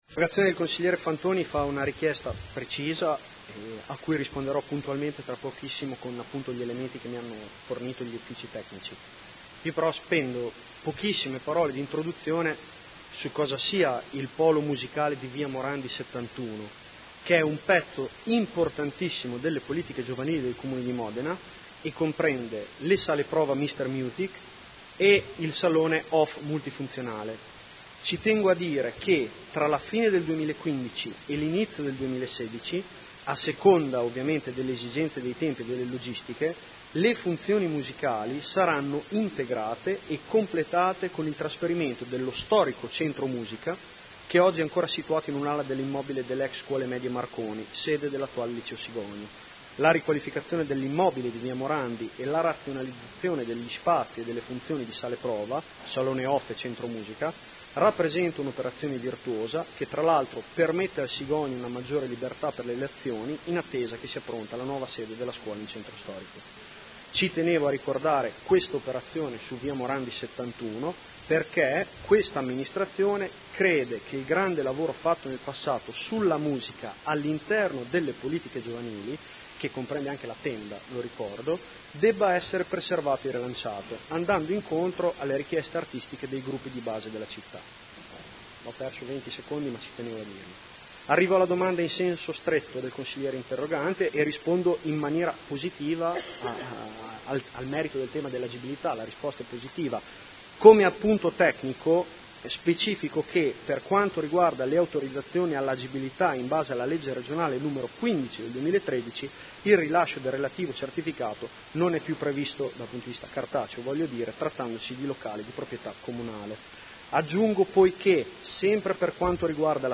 Seduta del 22 ottobre. Interrogazione del Gruppo Consiliare Movimento 5 Stelle avente per oggetto: Situazione locale denominato “Mr Musik Off”. Risponde l'assessore